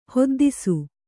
♪ hoddisu